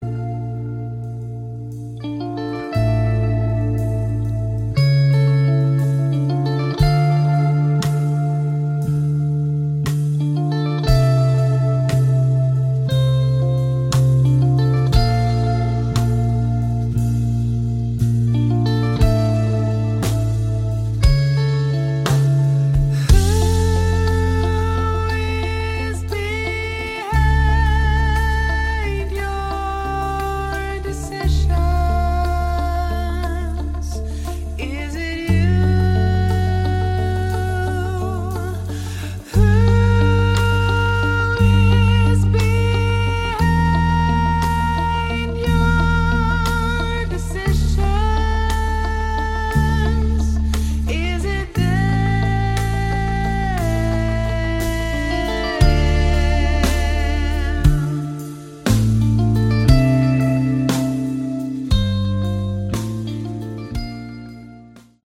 Category: Prog/Hard Rock
lead vocals, bass
guitars
drums